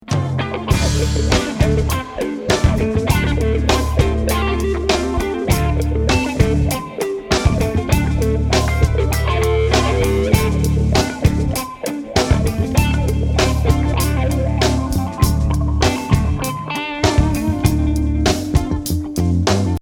je suis en train de restaurer un vieux enregistrement live que j'avais fait lors d'un concert de mon groupe...
une fois la nouvelle caisse-claire mixé dans la batterie existante cela donne un résultat assez correct ! comparez donc l'extrait-2 d'en haut avec celui-ci: